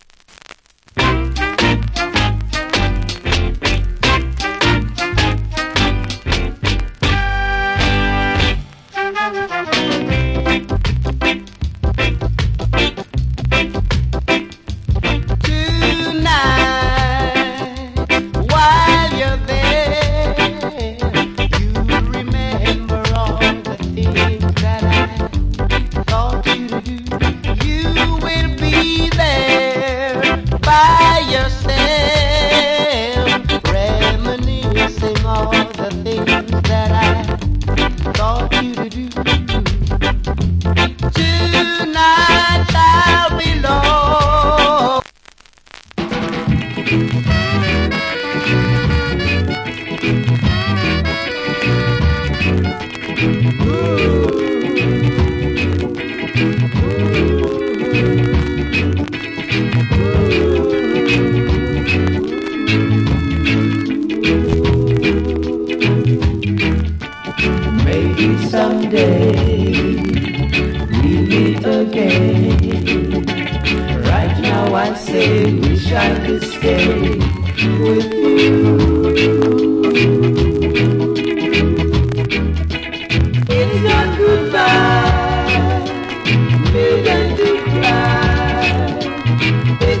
Early Reggae Vocal.